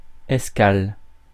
Ääntäminen
IPA: /ɛs.kal/